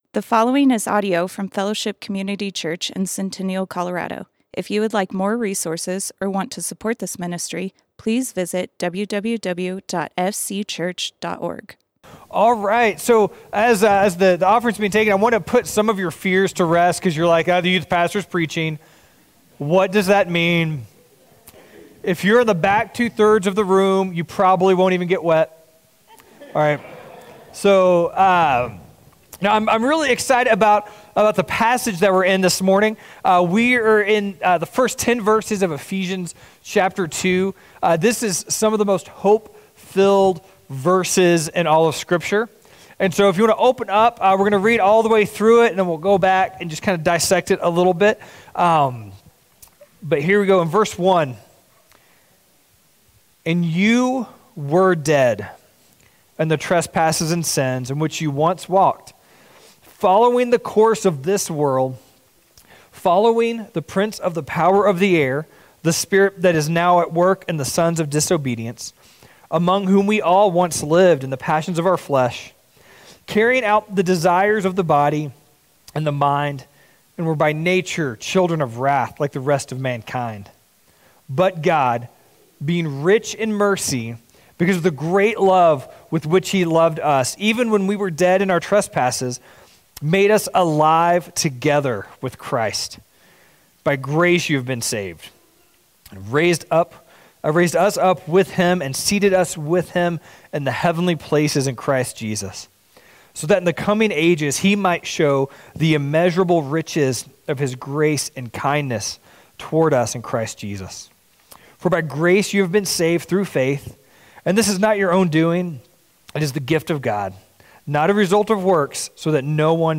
Fellowship Community Church - Sermons A New Way to Walk Play Episode Pause Episode Mute/Unmute Episode Rewind 10 Seconds 1x Fast Forward 30 seconds 00:00 / 42:04 Subscribe Share RSS Feed Share Link Embed